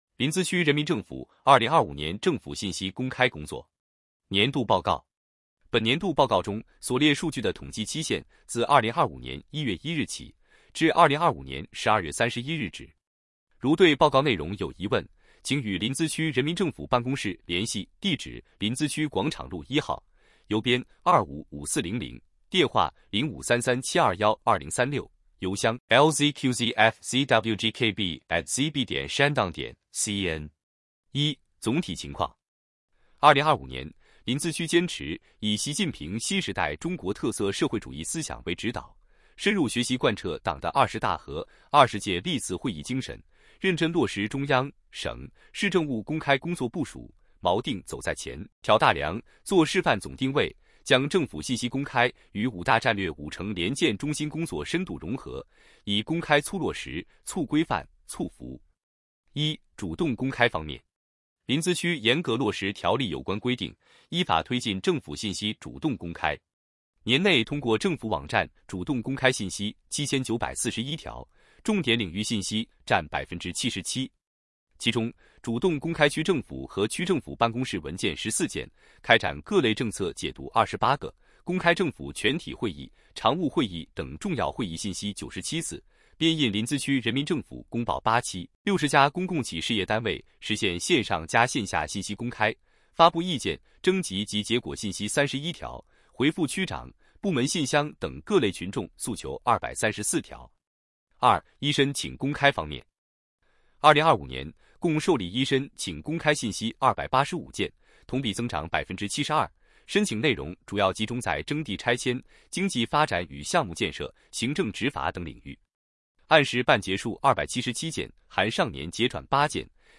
语音播报